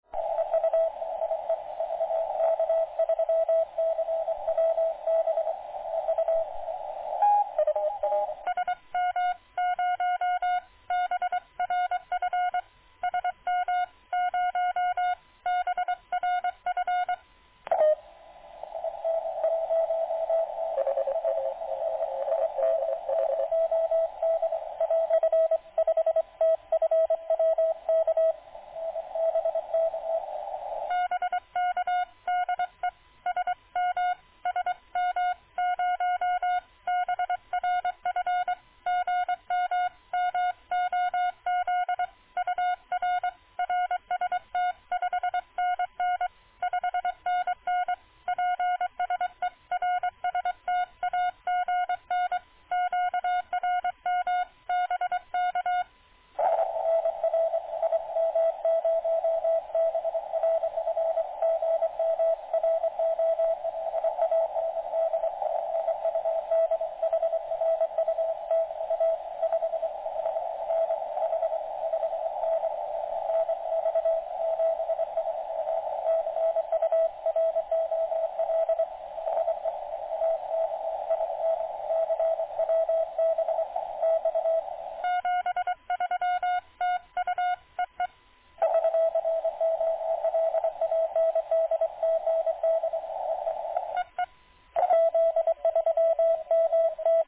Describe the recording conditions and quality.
Local noise seems to increase every year. Now at S7 to S8 with new rattle.